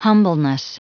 Prononciation du mot humbleness en anglais (fichier audio)
Prononciation du mot : humbleness